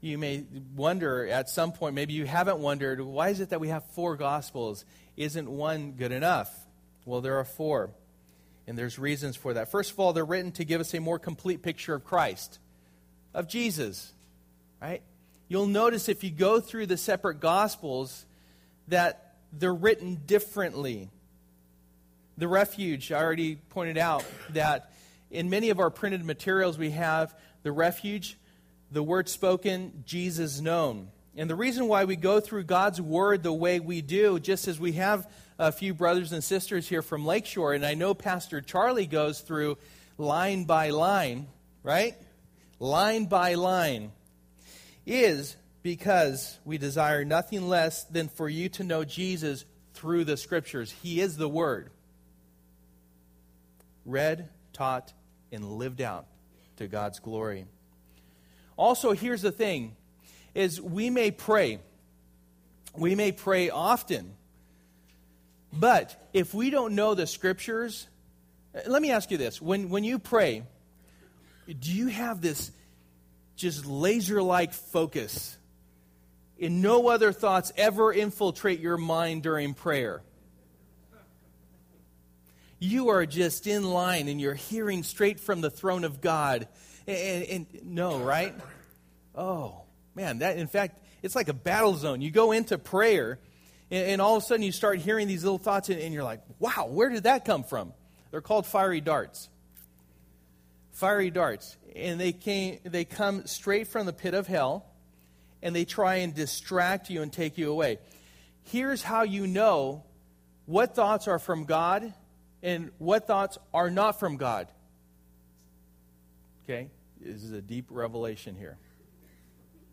Passage: John 1:6-28 Service: Sunday Morning